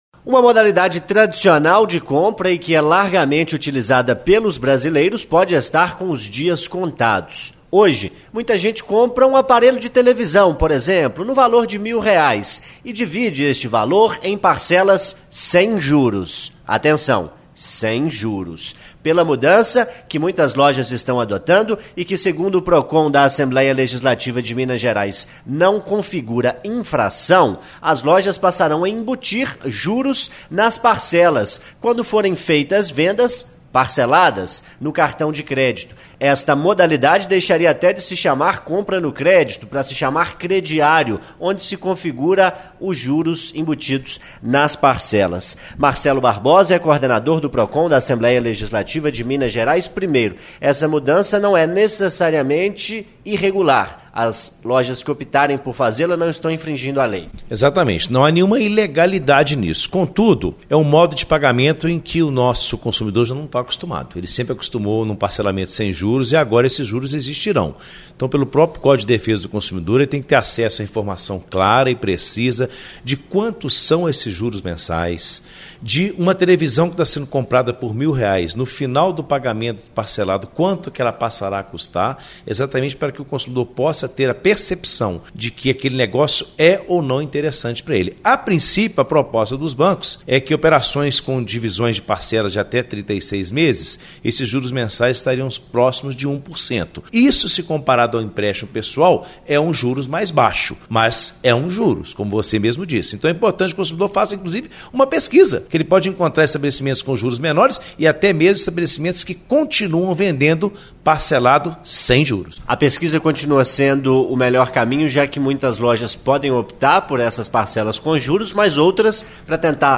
ENTREVISTA EXIBIDA NA RÁDIO ITATIAIA